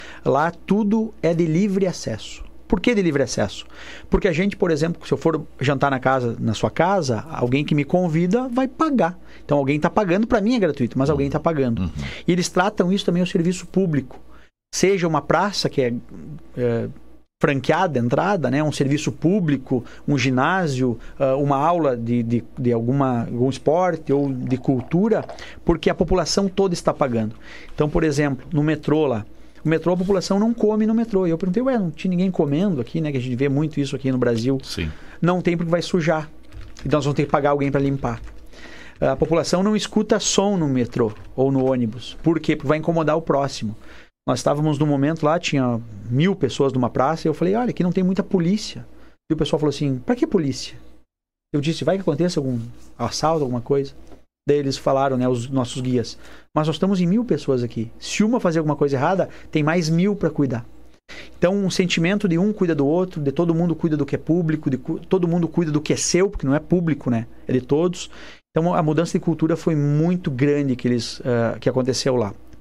Na manhã desta quinta-feira, o prefeito de Ijuí, Andrei Cossetin, participou do programa Fatorama, onde compartilhou informações sobre uma recente viagem a Medellín, na Colômbia.
Acompanhe um trecho da fala do prefeito de Ijuí, Andrei Cossetin: